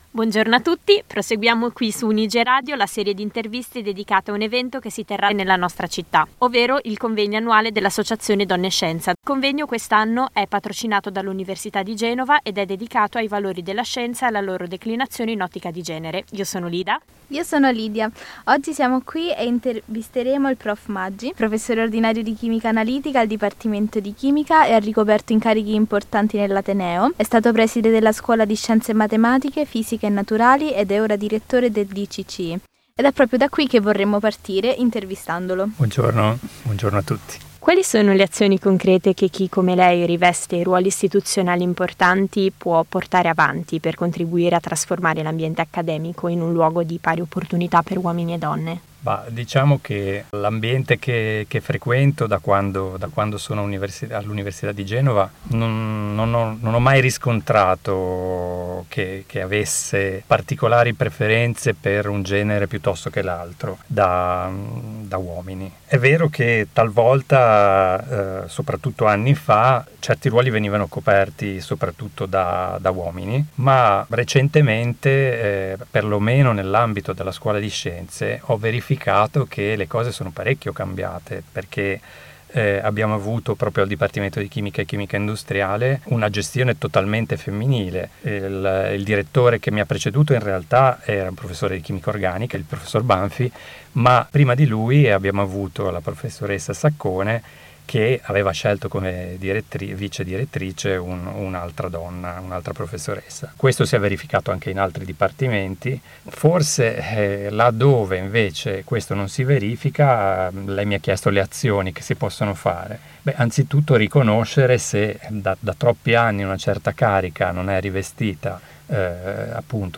Intervista: